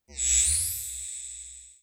Turn On Device.wav